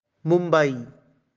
Mumbai (Marathi: Mumbaī, pronounced [ˈmumbəi],
Mumbai_Pronunciation.ogg.mp3